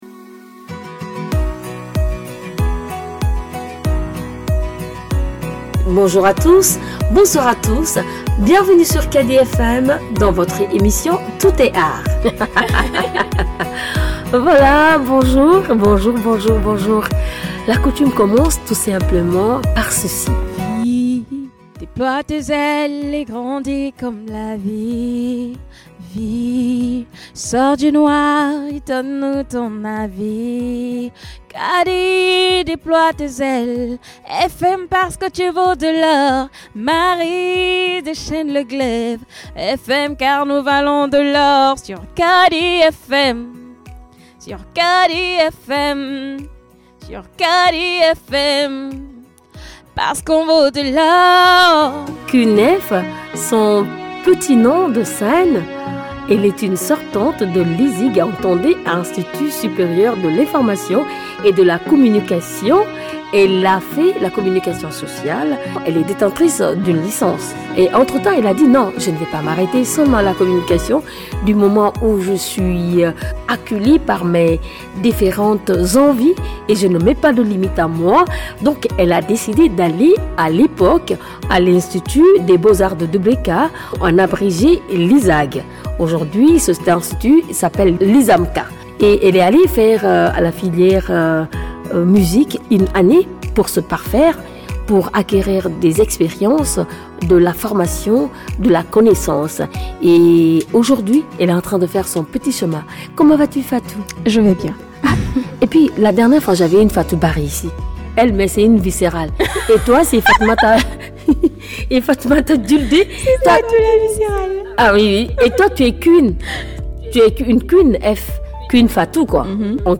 Emission